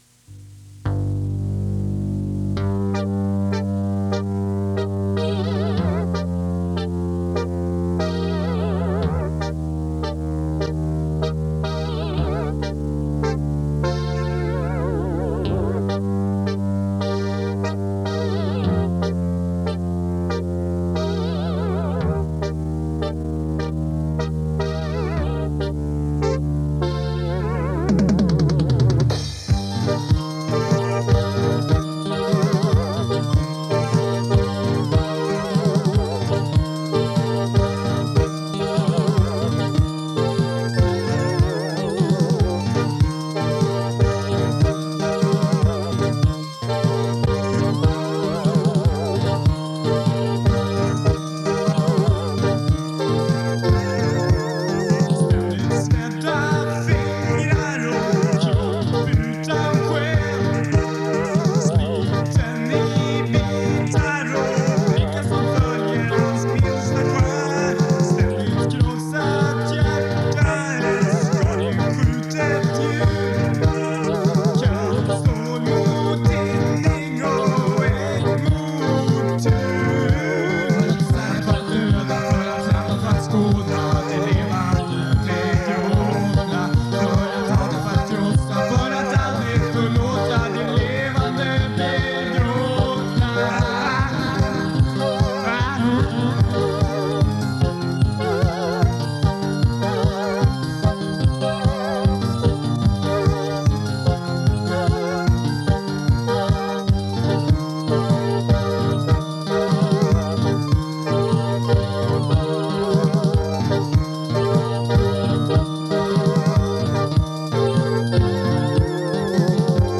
Gå ännu djupare i nostalgin med demon av Levande begråtna